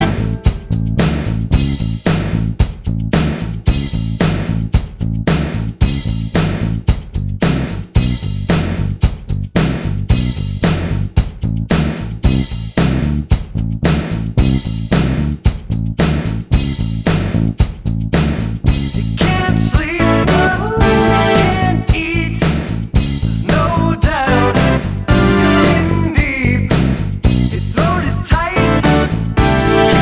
No Main Guitars Rock 4:24 Buy £1.50